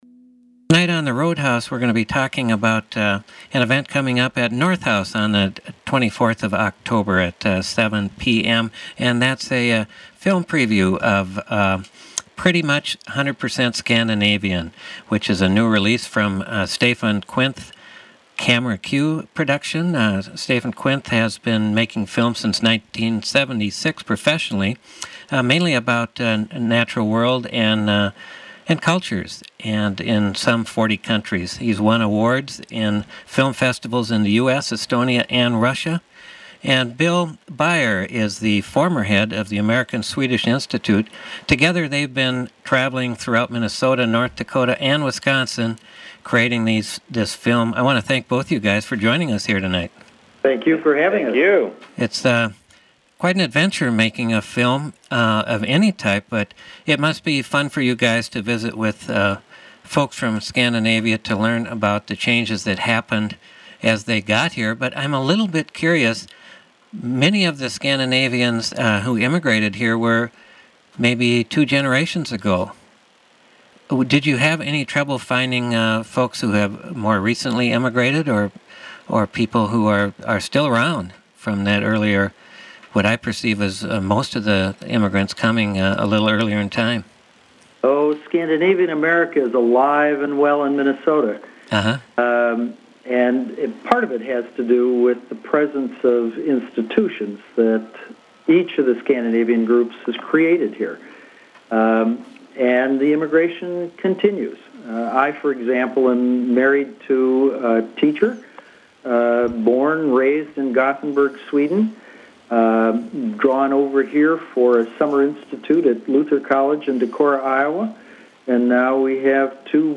Listen to the interview. Program: The Roadhouse